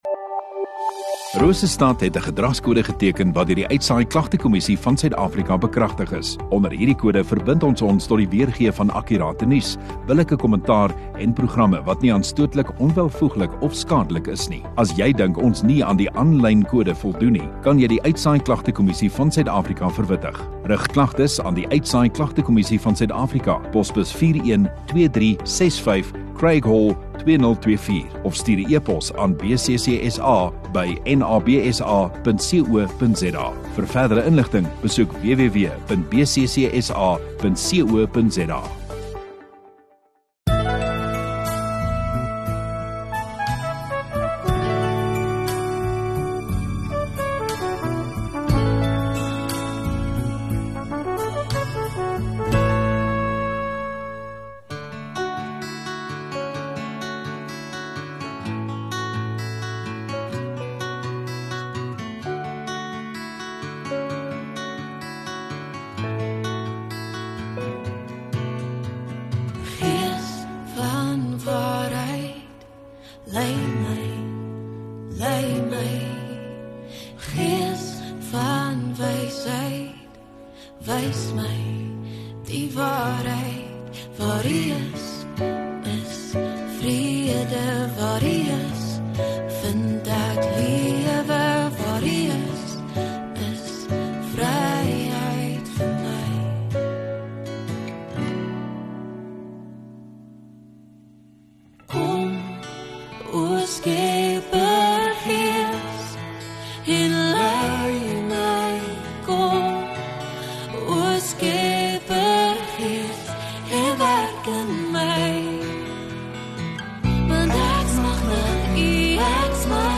14 Jun Saterdag Oggenddiens